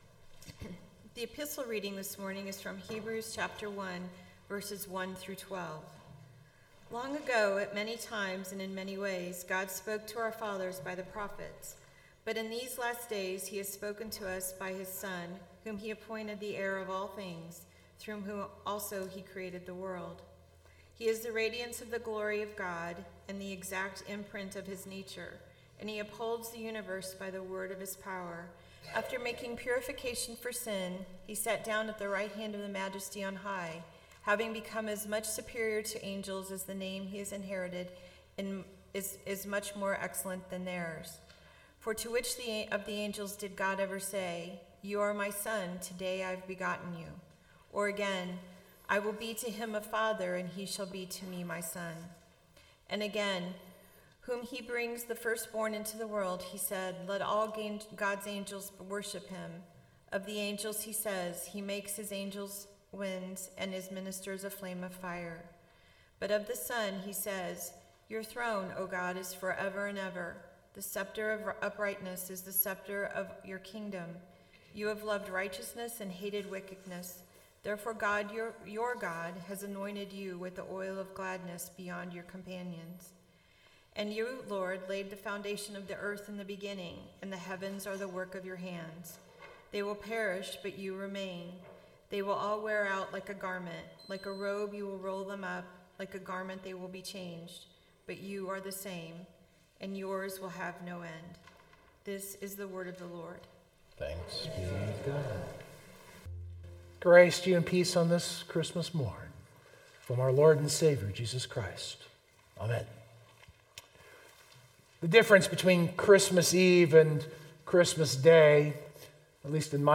In this sermon I try to build off of the immediately prior context of parables, explanation and the feeding of the 5000.